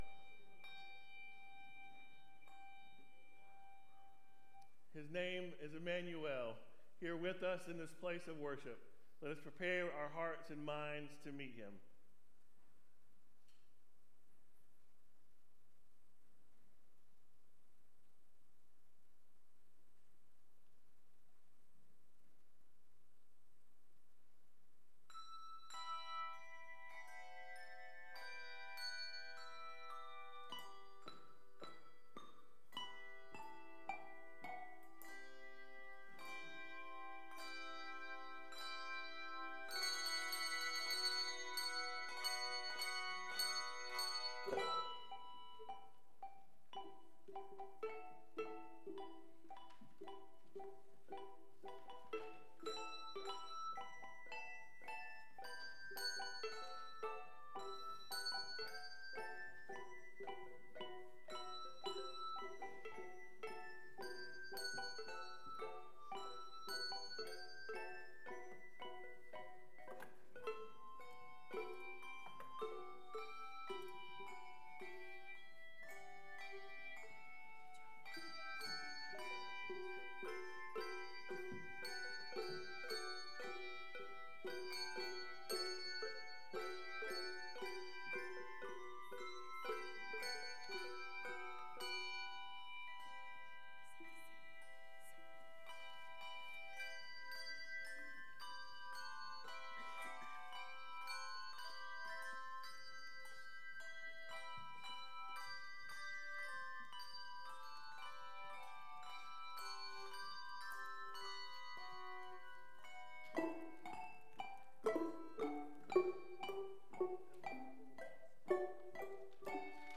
Matthew 2:1-12 Service Type: Morning Bible Text